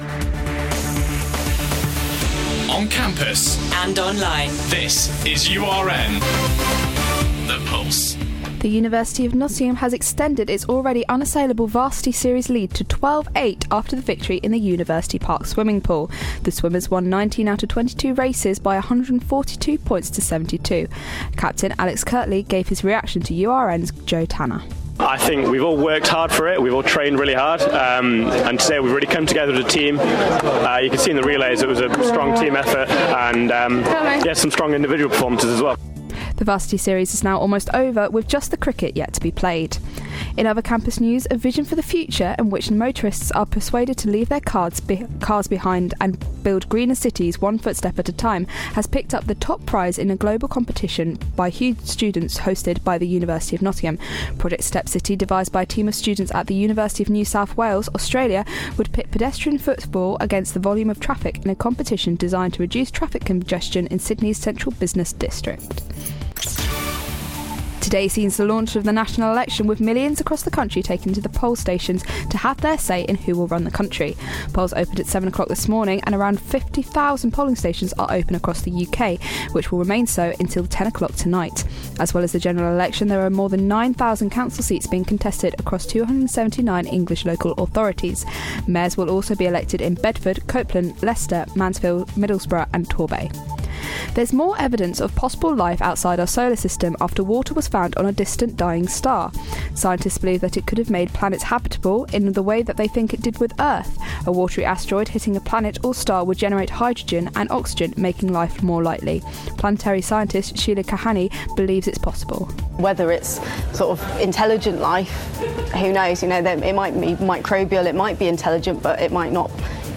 Your latest headlines - 7th May 2015